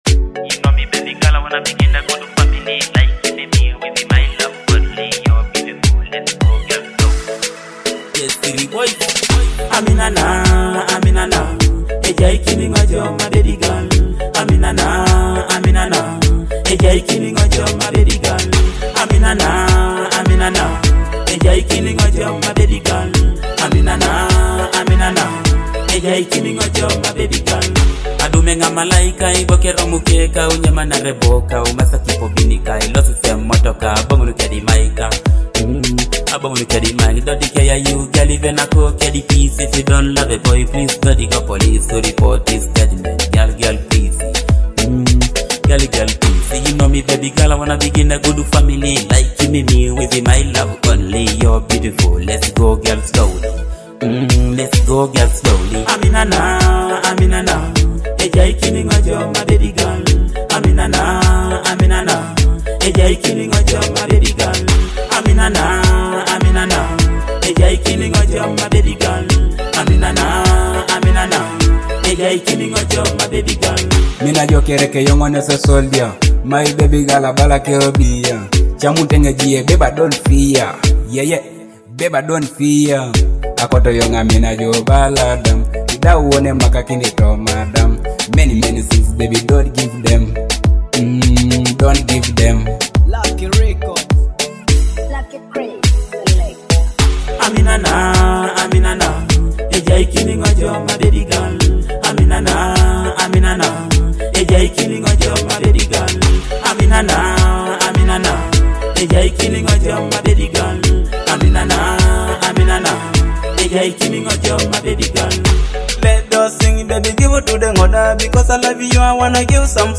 a fresh Teso hit